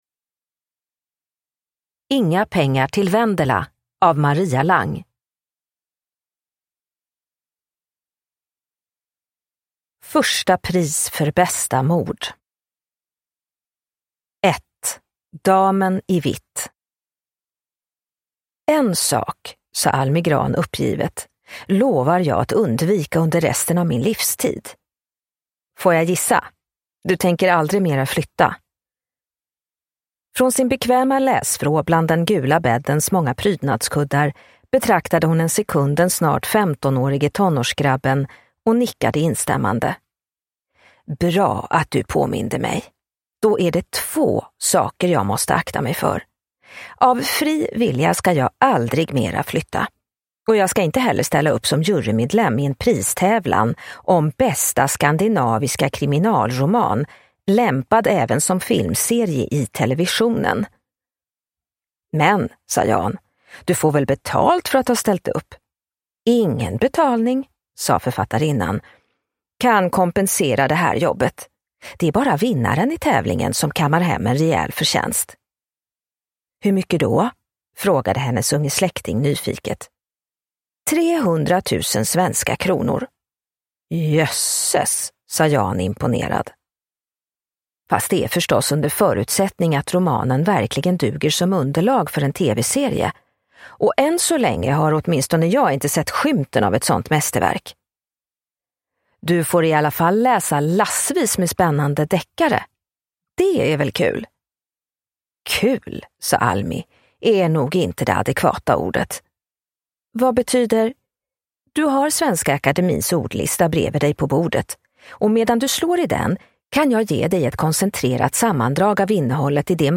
Inga pengar till Vendela – Ljudbok – Laddas ner